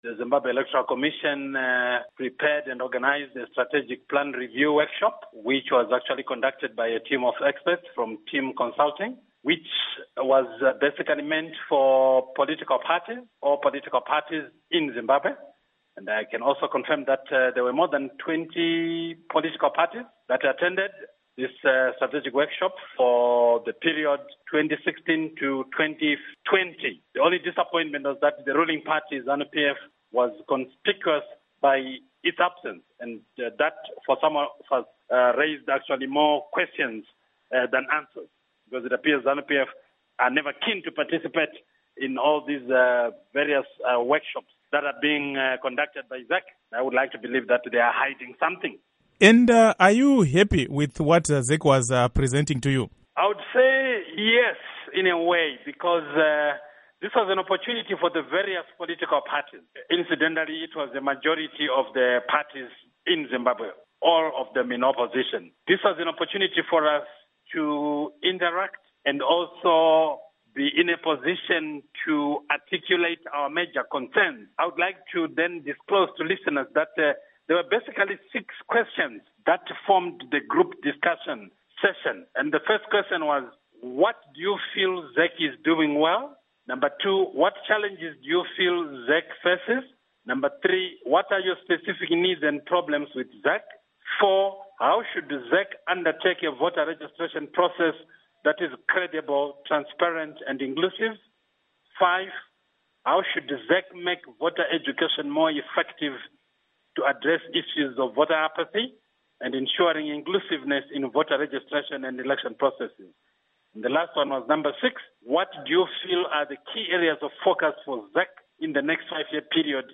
Inteview With Obert Gutu on ZEC Strategic Meeting